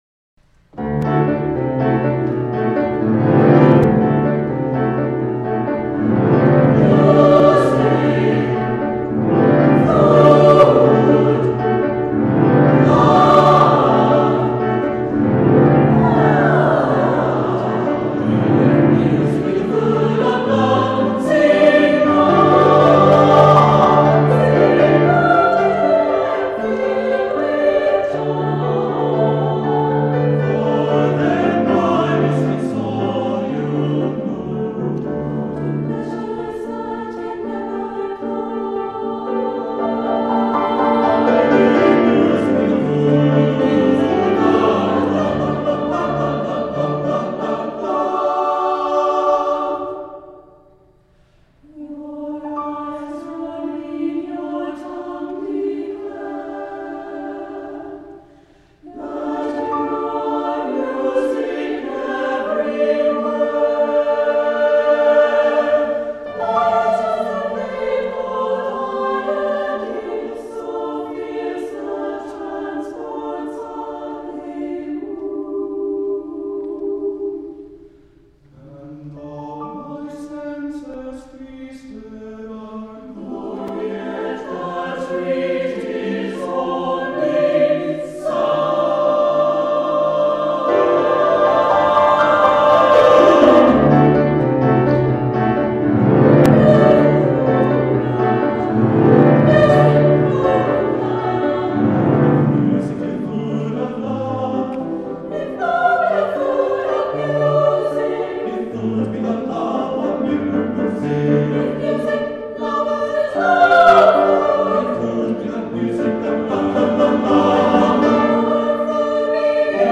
for SATB Chorus and Piano (2003)